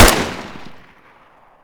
ump45_shoot1.ogg